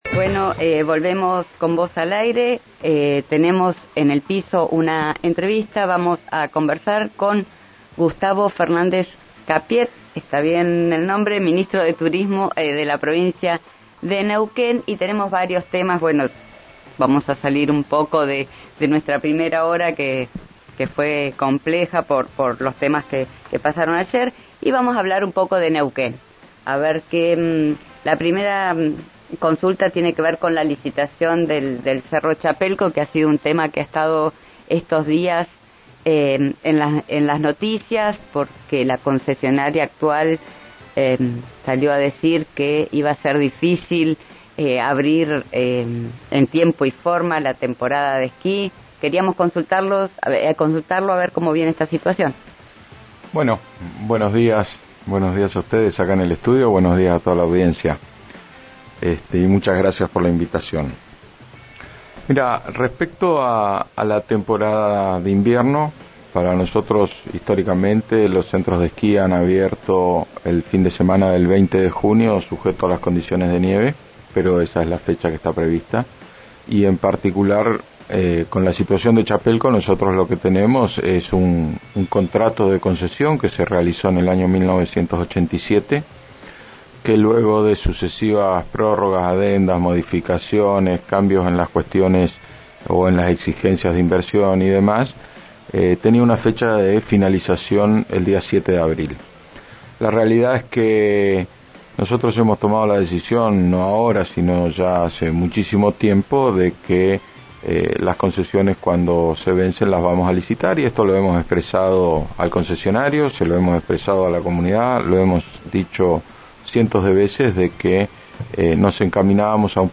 Escuchá al ministro de Turismo, Gustavo Fernández Capiet, por RÍO NEGRO RADIO:
En diálogo con RÍO NEGRO RADIO, el funcionario indicó que la fecha prevista para la apertura de la temporada invernal es, como sucede habitualmente, el fin de semana del 20 de junio.